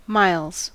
Uttal
Uttal US Okänd accent: IPA : /mɑɪlz/ Ordet hittades på dessa språk: engelska Ingen översättning hittades i den valda målspråket.